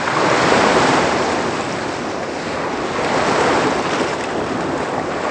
se-ocean.wav